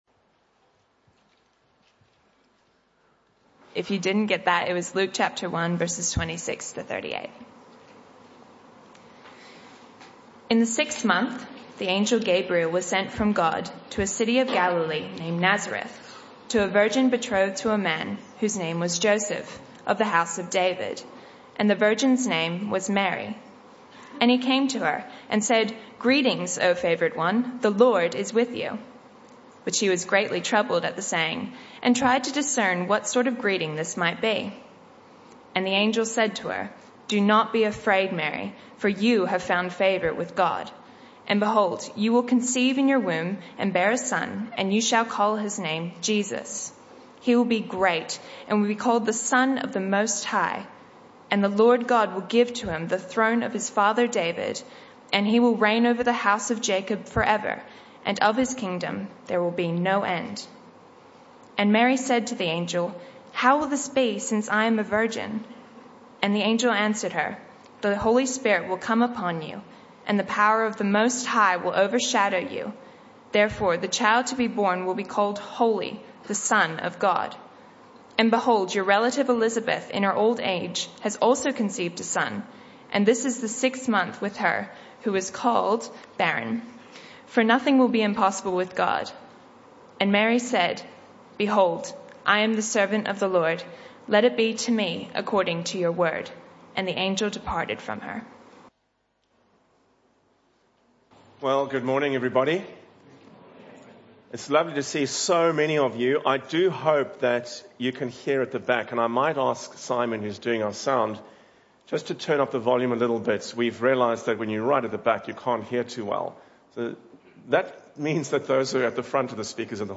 This talk was part of the AM & PM Service series entitled The Long Road To Christmas (Talk 4 of 4).